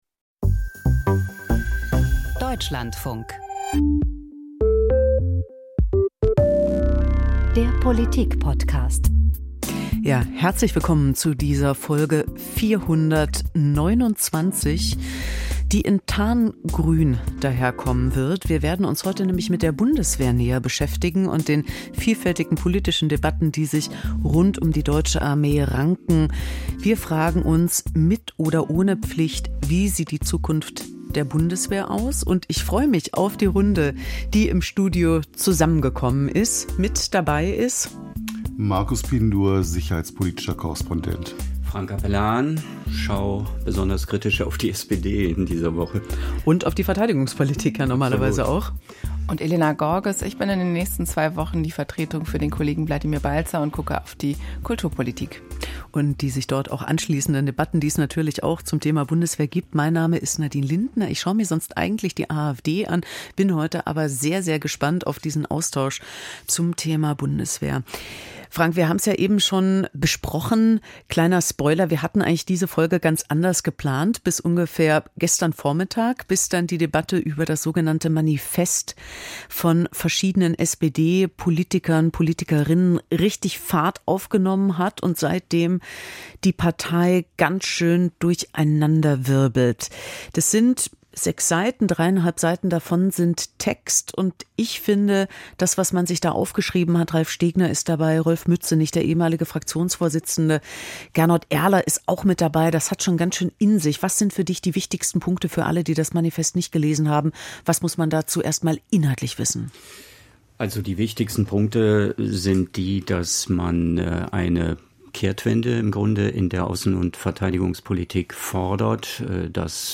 Gut recherchiert, persönlich erzählt – das ist die Reportage von Deutschlandfunk Kultur.